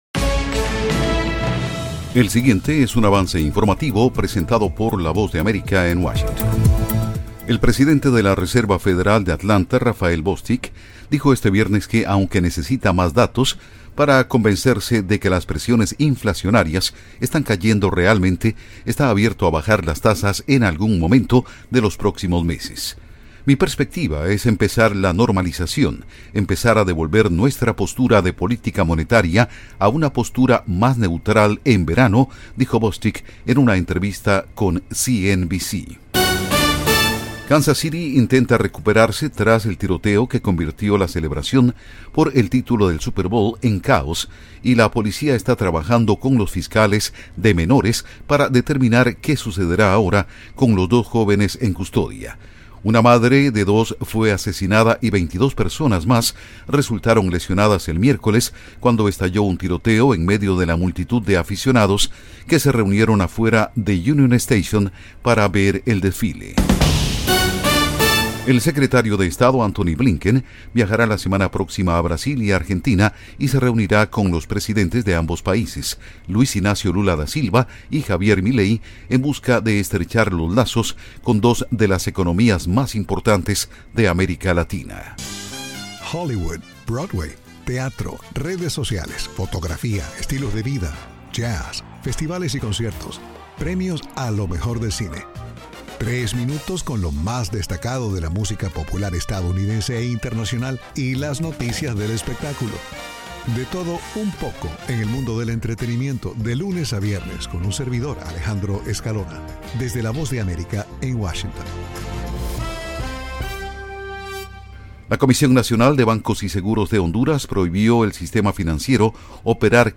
Avance Informativo 4:00 PM
El siguiente es un avance informativo presentado por la Voz de América en Washington.